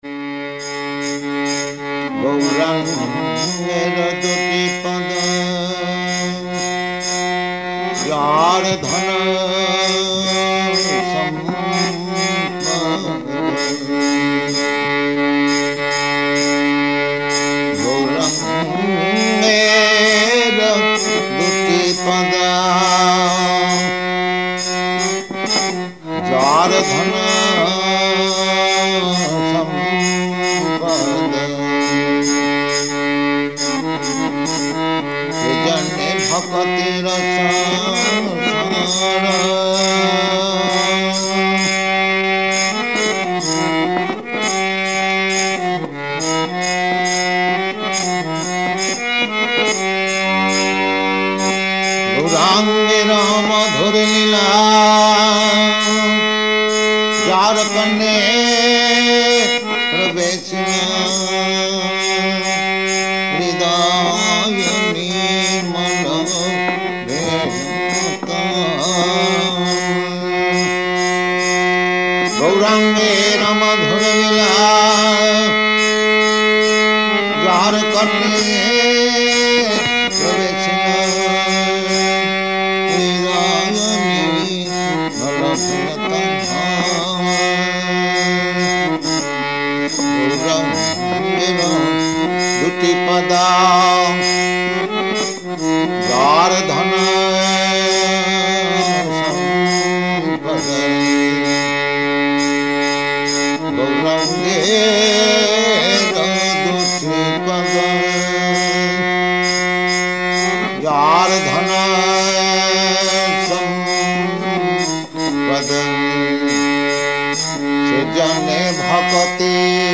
Type: Purport
Location: Los Angeles
Prabhupāda: [sings:]